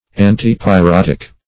Search Result for " antipyrotic" : The Collaborative International Dictionary of English v.0.48: Antipyrotic \An`ti*py*rot"ic\, a. (Med.) Good against burns or pyrosis.